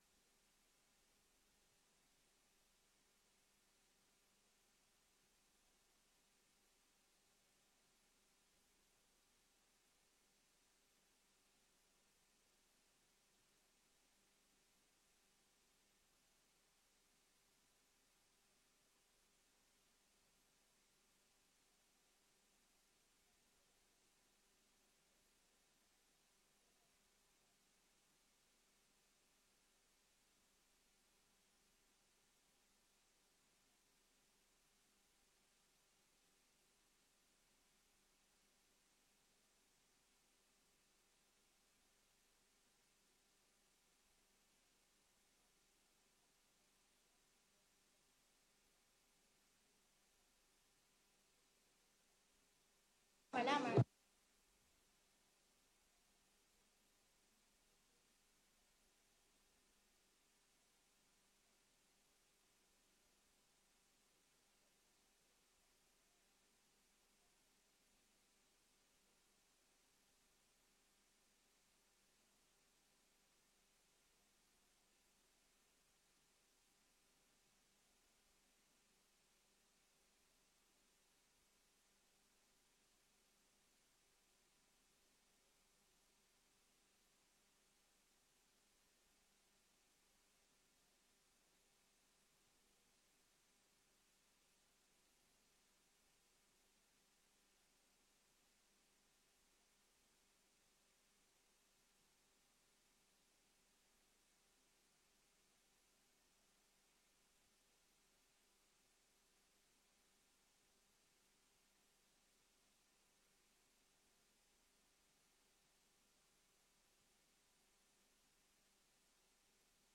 Openbare vergadering
Locatie: Raadzaal